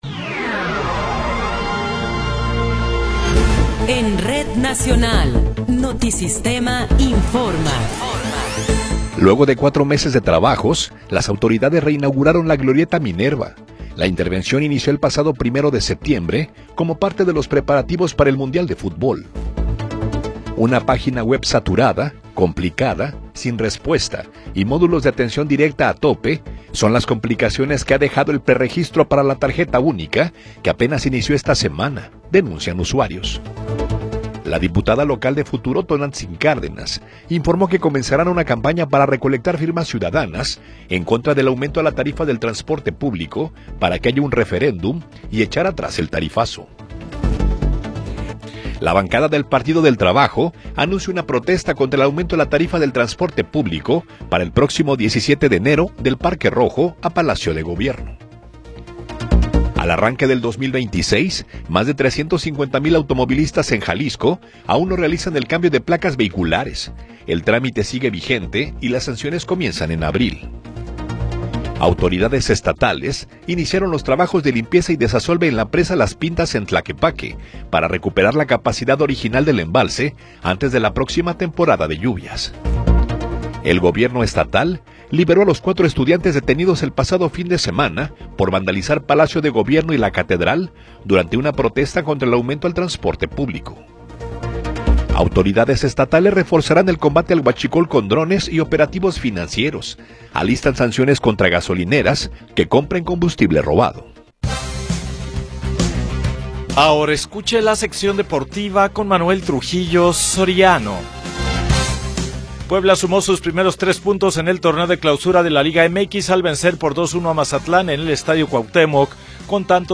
Noticiero 21 hrs.
Resumen informativo Notisistema, la mejor y más completa información cada hora en la hora.